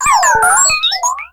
Catégorie:Cri Pokémon (Soleil et Lune) Catégorie:Cri de Spododo